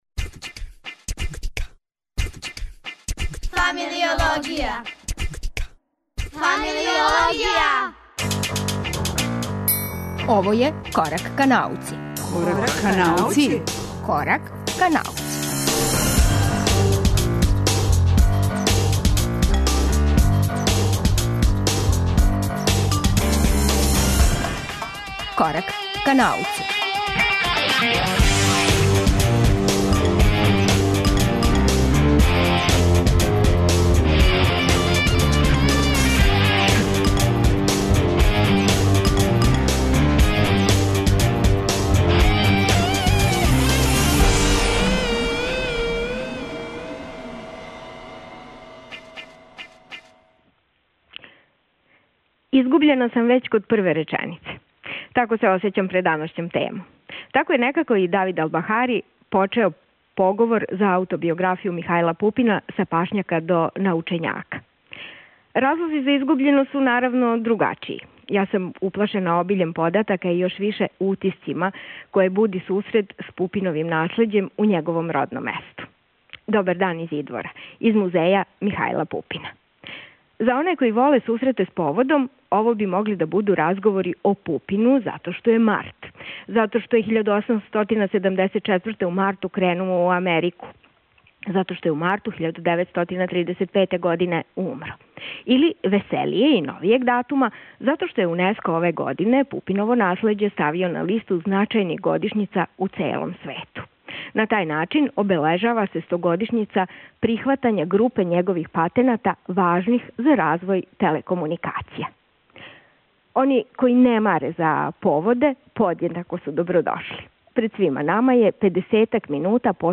Емисију 'Корак ка науци' данас реализујемо из Идвора, родног места великог научника Михајла Пупина. Током емисије чућемо приче о нашем великом научнику и његовим достигнућима.